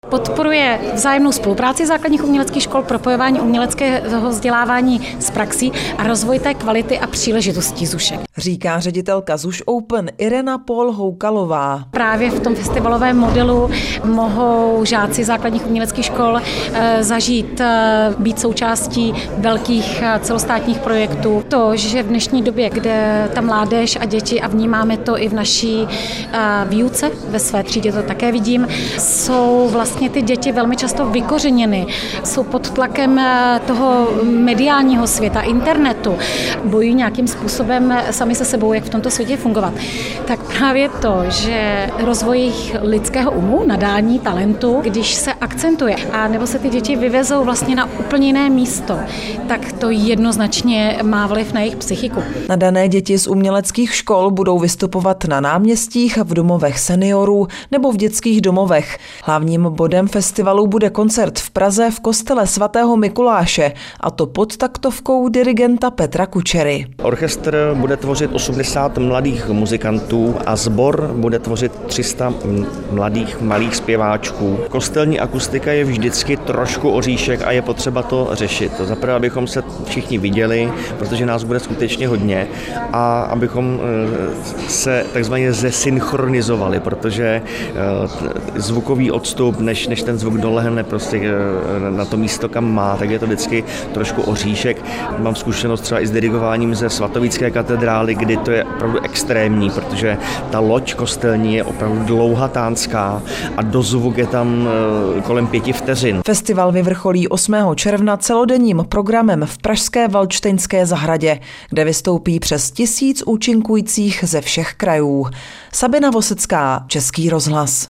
Zprávy Českého rozhlasu Region: Letošní ZUŠ Open nabízí vystoupení stovek dětí.